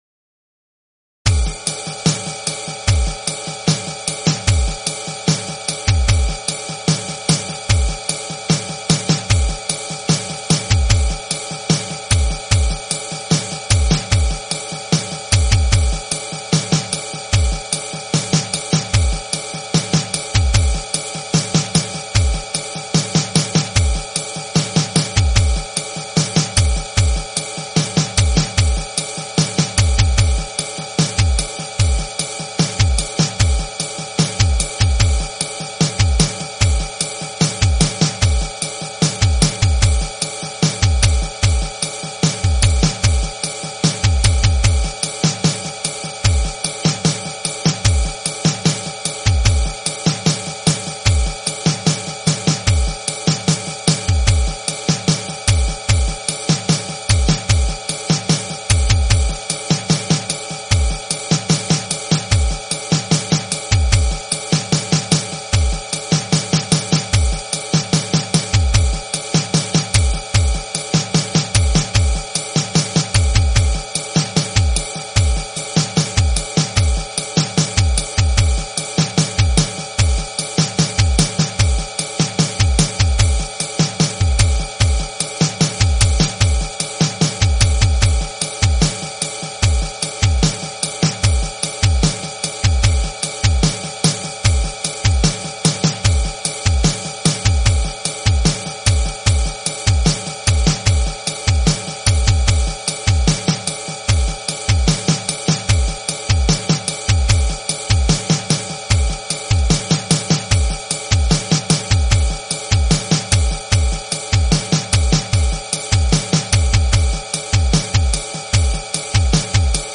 This time, let's imagine a 4:4 framing kick-snare beat (on 1 and 3, with ride ongoing).